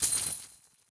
Special & Powerup (15).wav